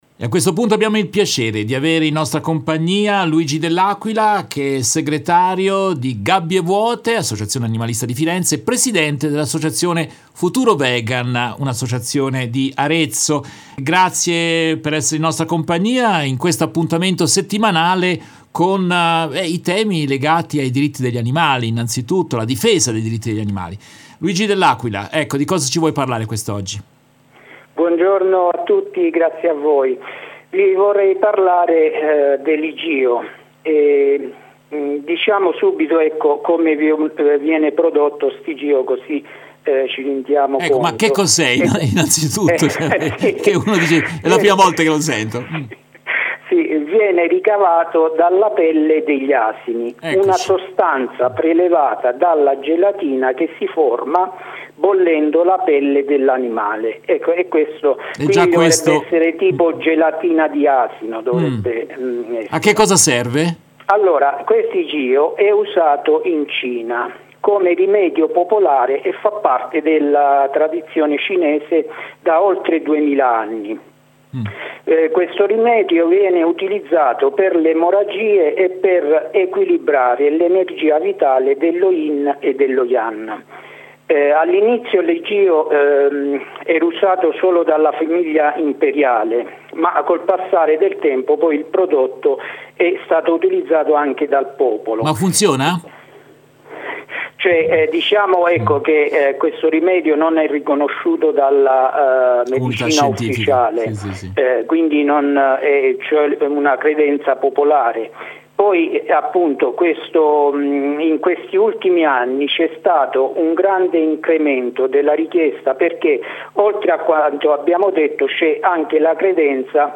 Nel corso della diertta del mattino di RVS del 15 marzo 2024